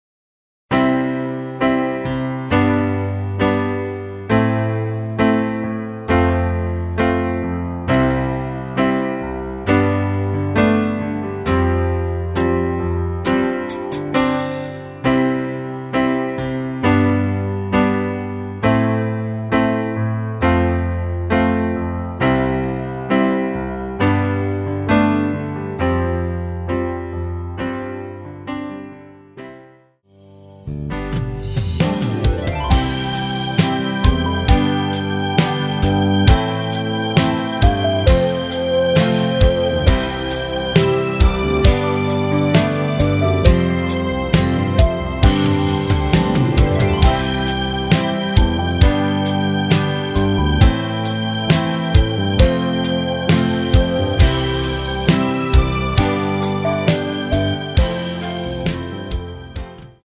전주없이 시작하는 곡이라 전주 4마디 만들어 놓았습니다.
하이햇 소리 끝나고 노래 들어가시면 됩니다.
앞부분30초, 뒷부분30초씩 편집해서 올려 드리고 있습니다.
중간에 음이 끈어지고 다시 나오는 이유는